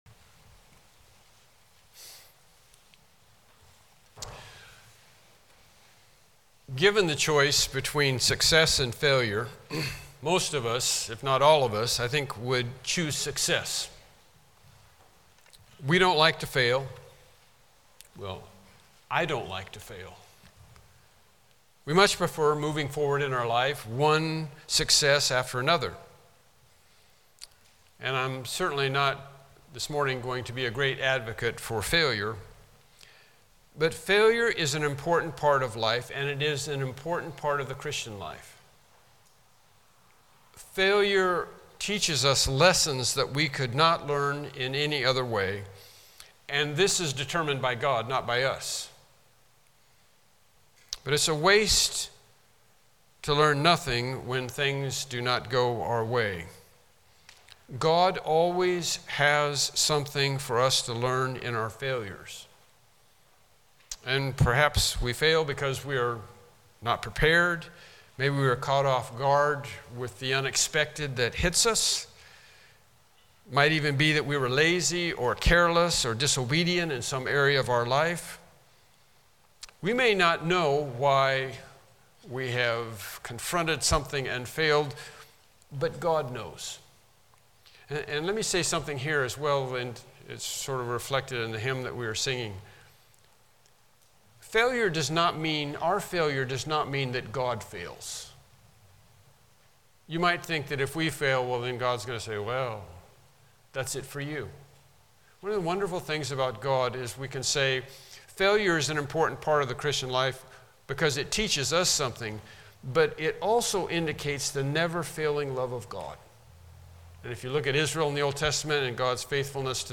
Passage: Mark 14:37-42 Service Type: Morning Worship Service « Lesson 11